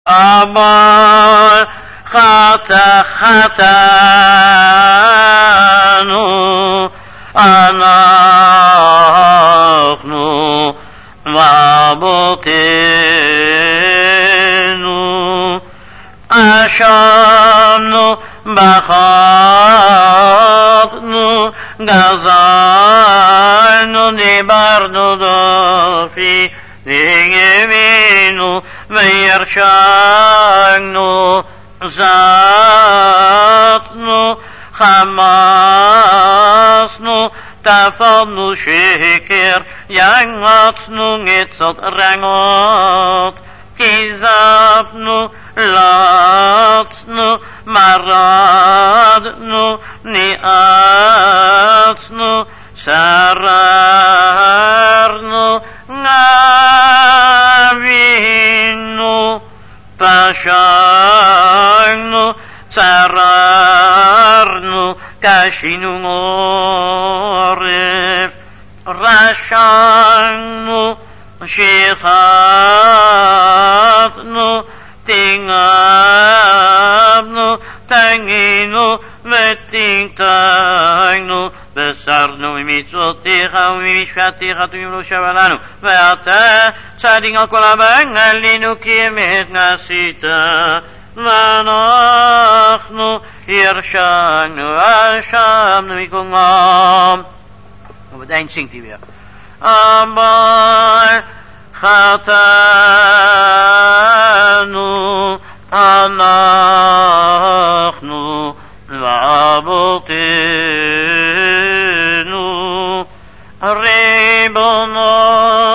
Vidui